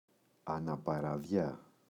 αναπαραδιά, η [anapara’ðʝa]